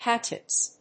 /ˈhætʃʌts(米国英語)/